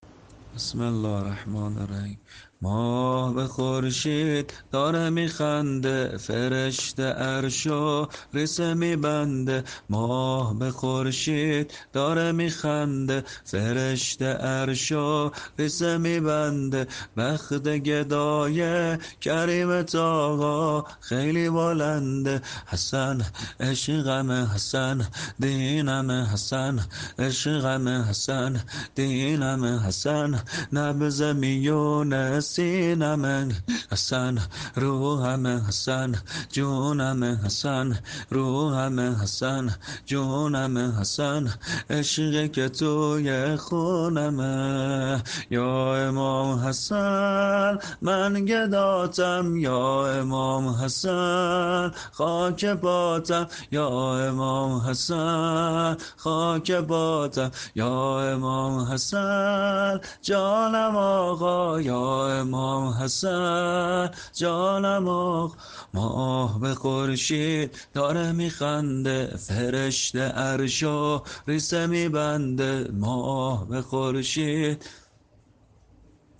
شب دوازدهم ماه مبارک رمضان۱۳۹۶